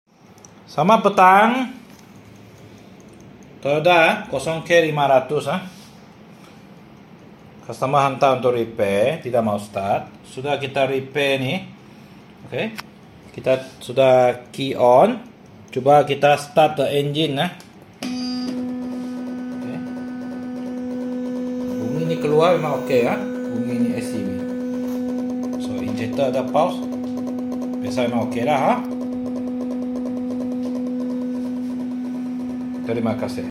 Toyota 89661 0K500 Hilux 2KD FTV sound effects free download
Toyota 89661 -0K500 Hilux 2KD-FTV engine ECU repair as cannot start repair & test run video.....